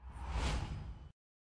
Play, download and share Newstransitionwhoosh original sound button!!!!
newstransitionwhoosh.mp3